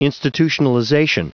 Prononciation du mot institutionalization en anglais (fichier audio)
Prononciation du mot : institutionalization